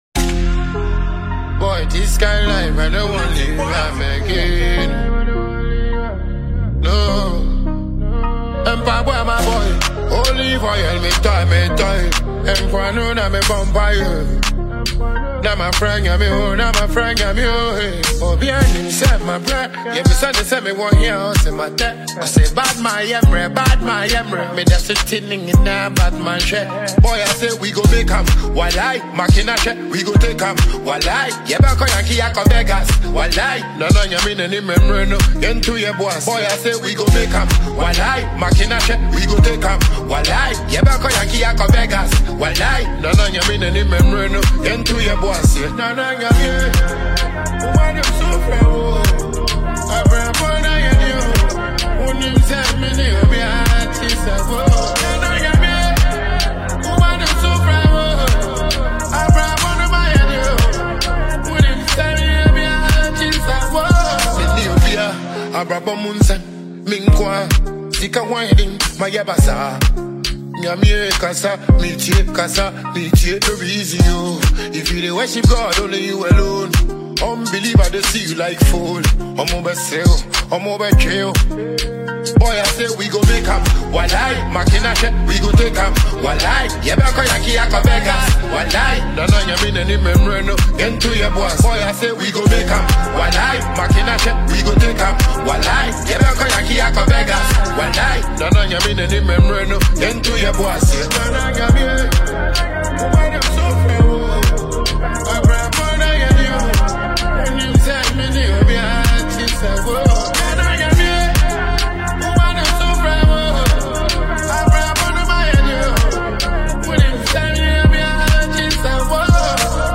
• Genre: Dancehall / Afrobeat / Inspirational